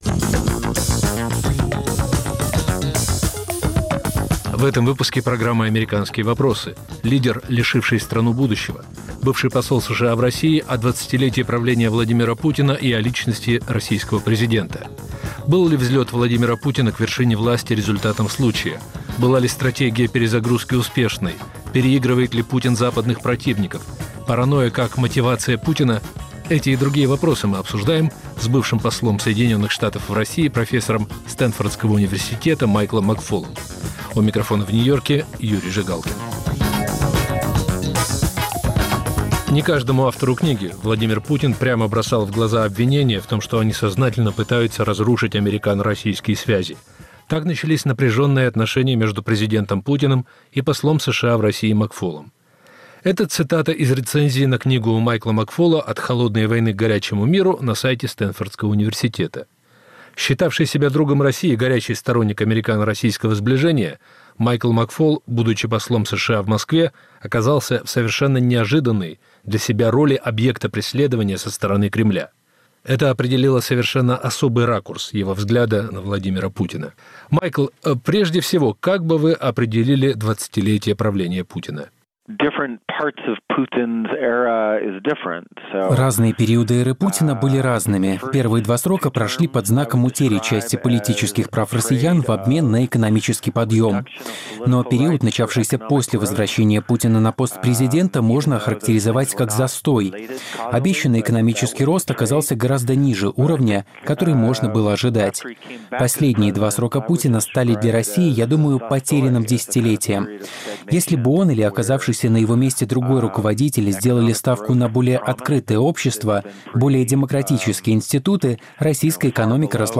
В передаче участвует профессор Стэнфордского университета, бывший посол США в России Майкл Макфол.